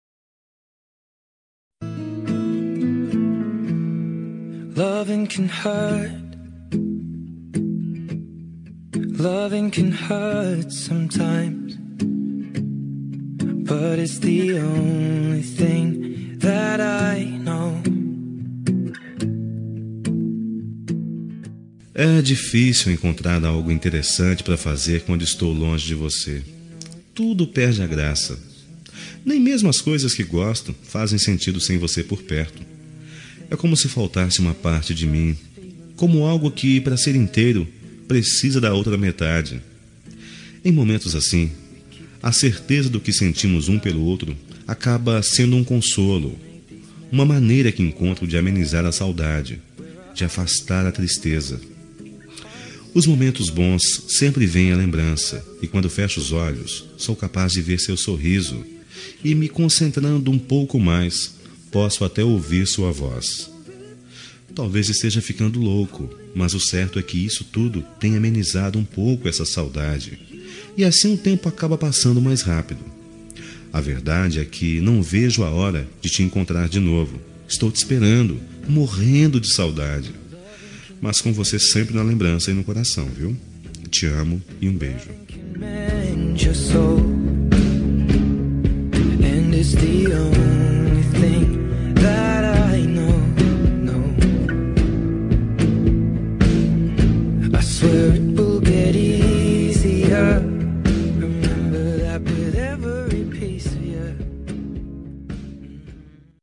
Telemensagem de Saudades – Voz Masculina – Cód: 4143